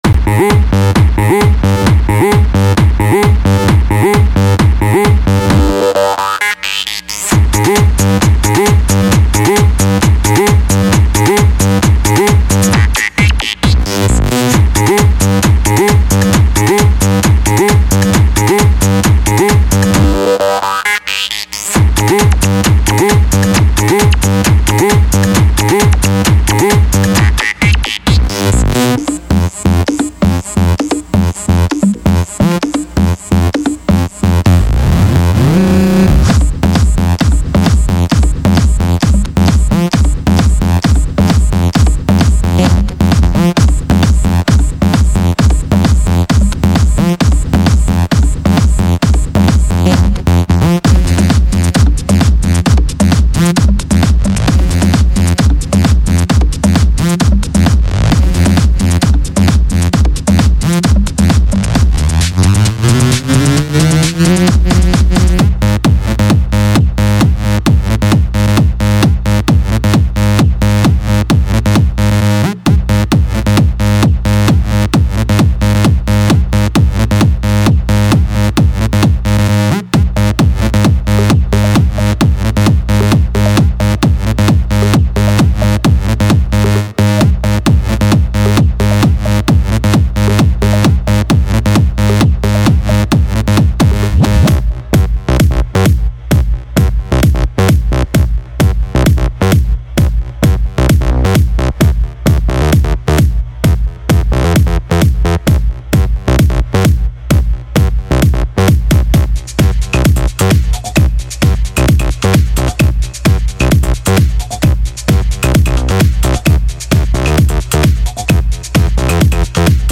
features 273 benassi style analog bassline phrases
with useful sidechain compression tweaks for variations and that
sought after pumping/ducking feel.
All loops are 100 royalty-free and recorded using analog gear.
separate; Kicks, HiHats,Percussions and Glitch Loop Combos